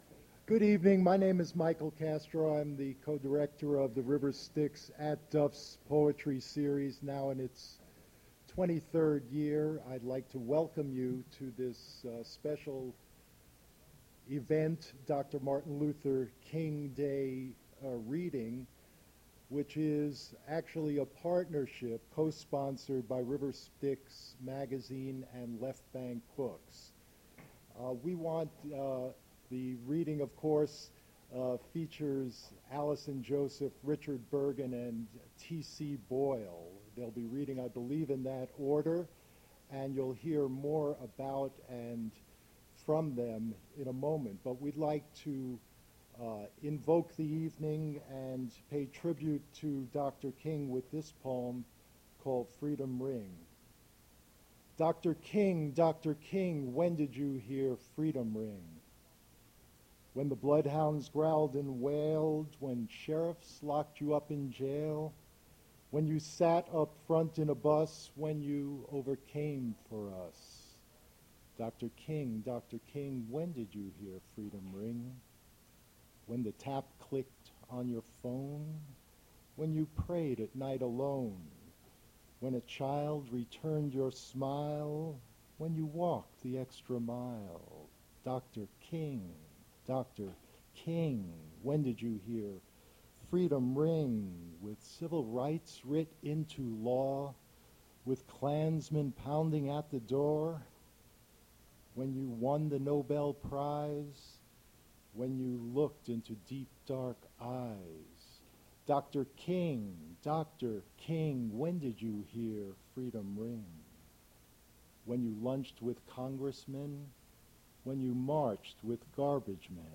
Poetry reading
reading their poetry at Duff's Restaurant for Martin Luther King Day.
mp3 edited access file was created from unedited access file which was sourced from preservation WAV file that was generated from original audio cassette.